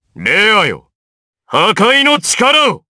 DarkKasel-Vox_Skill2_jp.wav